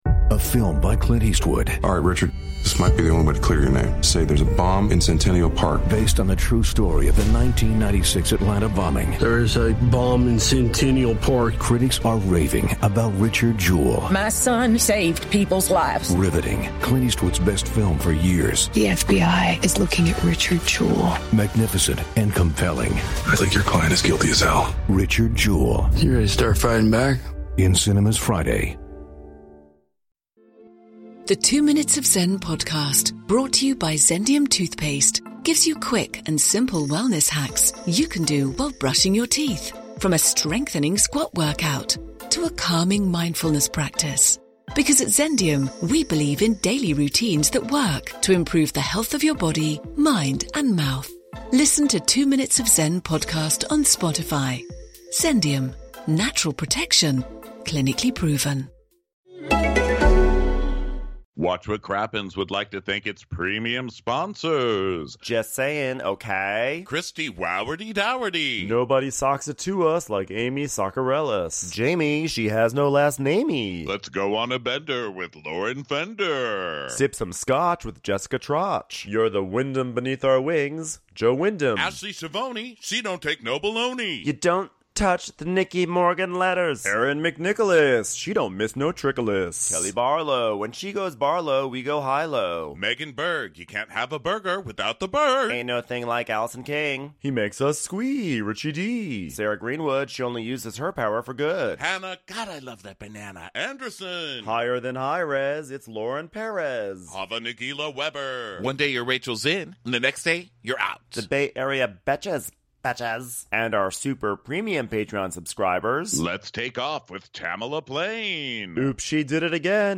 PumpRules: It's Brittany, Bach — Live from Austin